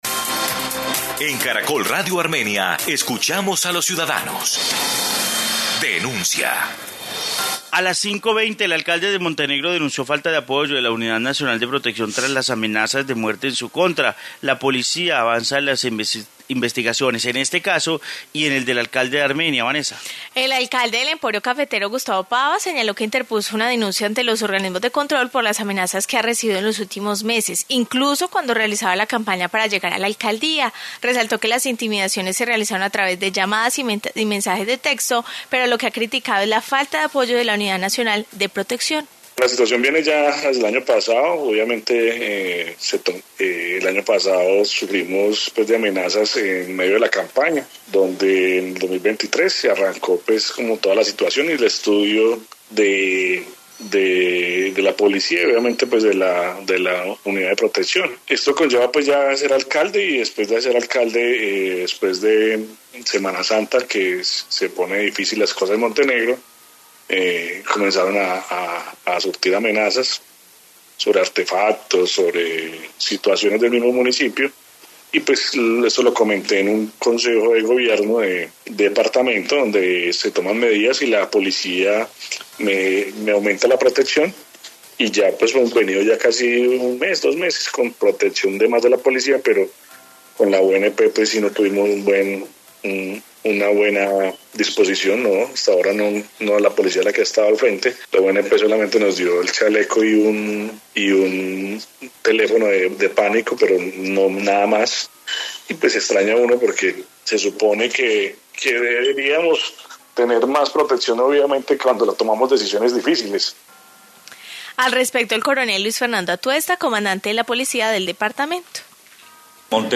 Informe caso amenazas